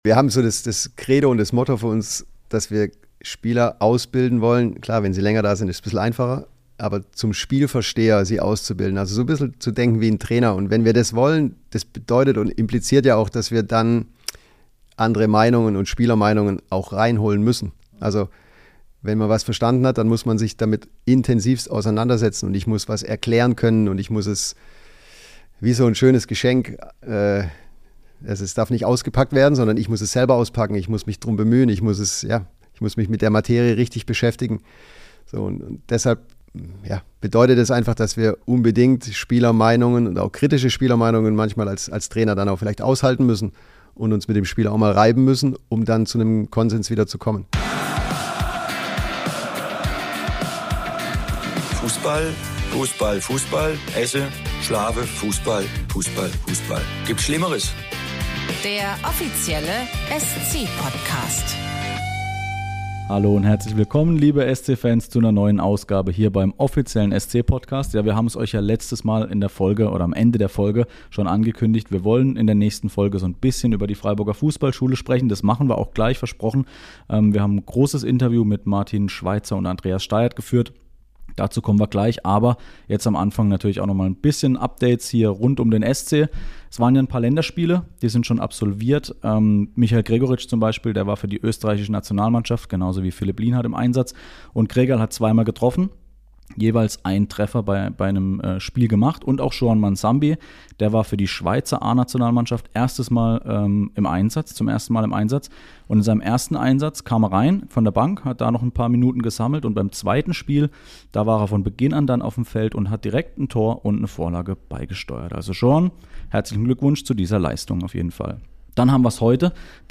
Natürlich steht der Sport im Mittelpunkt, aber auch gesellschaftliche Themen und aktuelle Vereinsnachrichten werden bei uns besprochen. Jeden Monat gibt es eine neue Folge, in der wir uns mit einem spannenden Gast über die neusten Entwicklungen im Europa-Park Stadion, dem Dreisamstadion oder der Freiburger Fußballschule unterhalten.